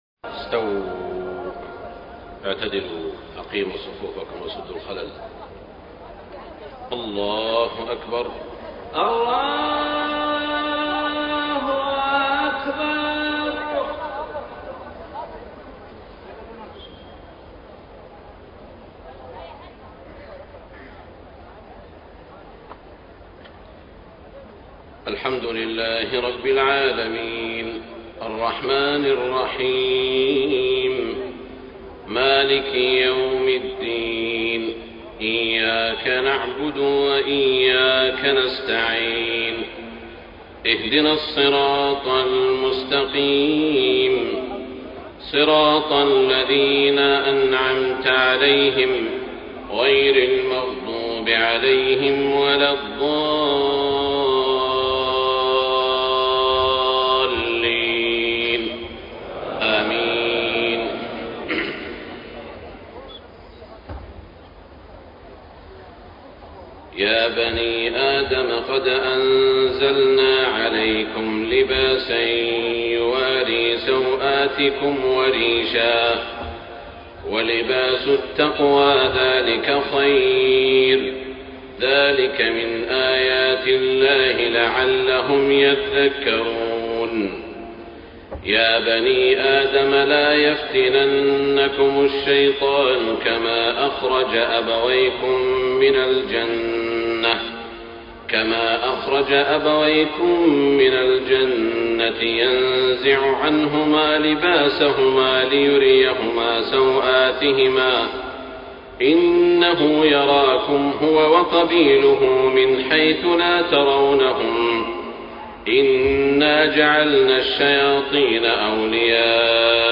صلاة العشاء 8 - 4 - 1434هـ من سورة الأعراف > 1434 🕋 > الفروض - تلاوات الحرمين